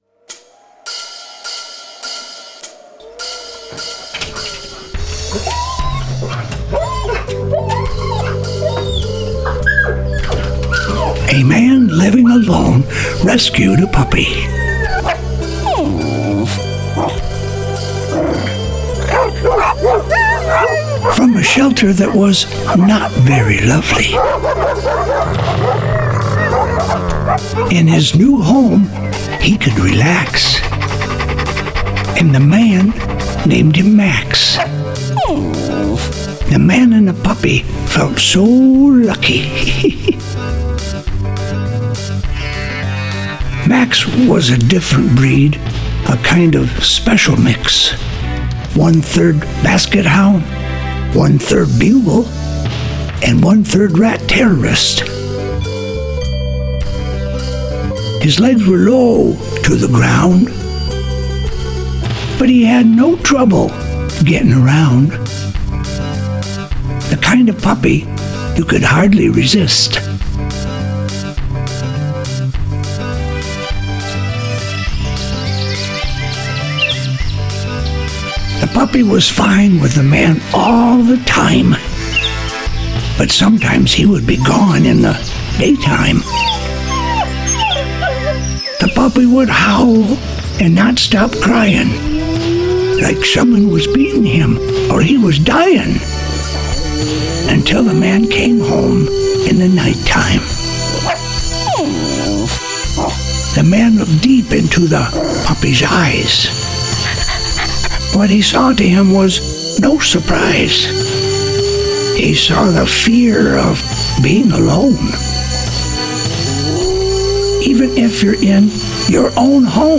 Audiobook 7:32